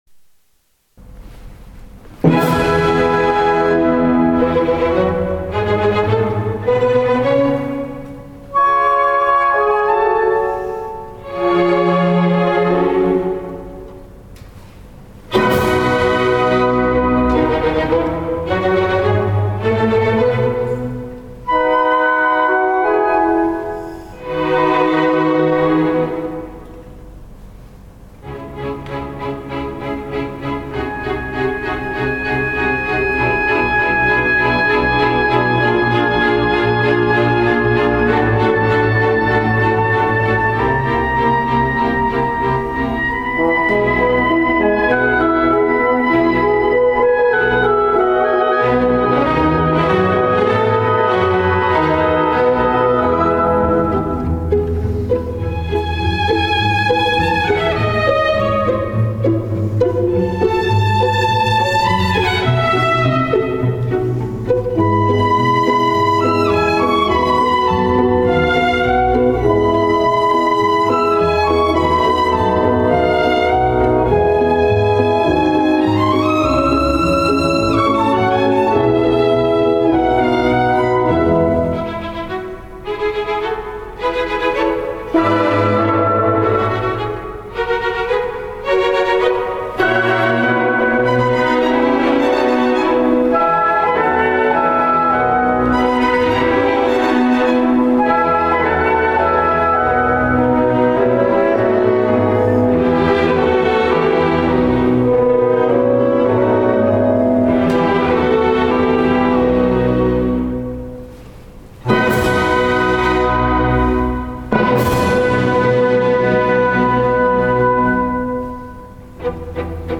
歌劇